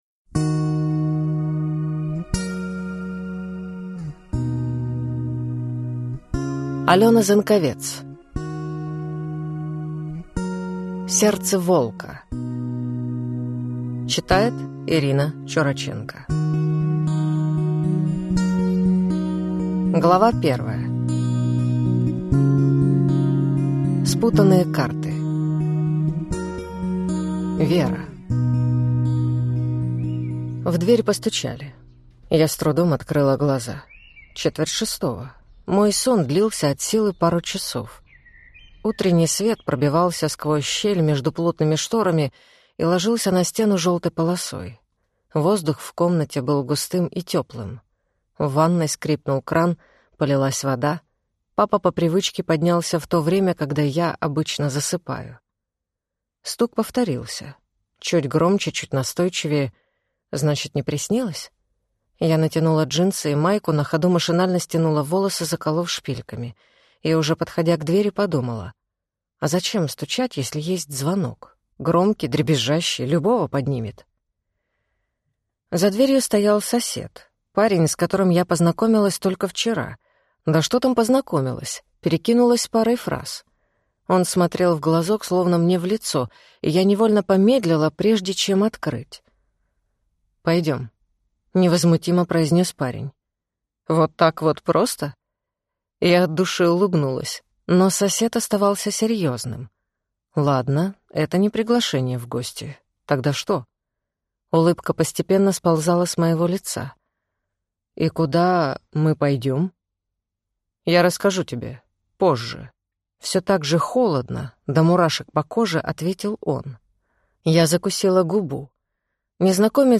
Аудиокнига Сердце волка | Библиотека аудиокниг